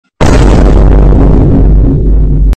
Thud Explosion Sound Effect Free Download
Thud Explosion